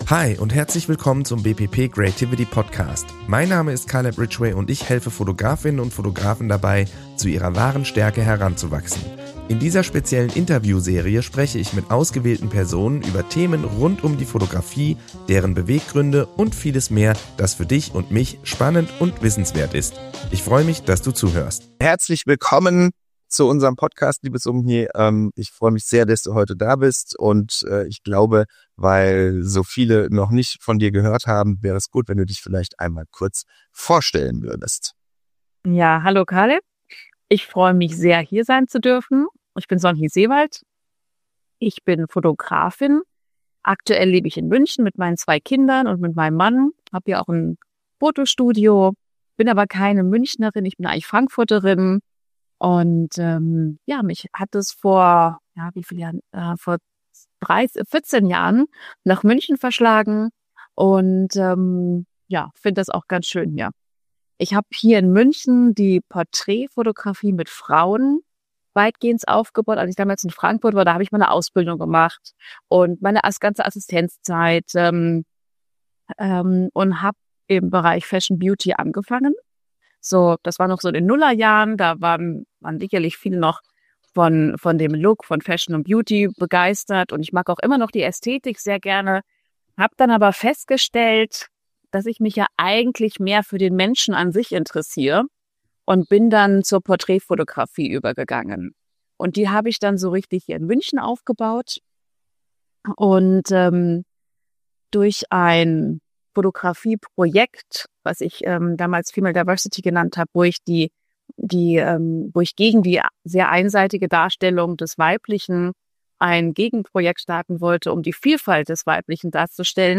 Interview Special 03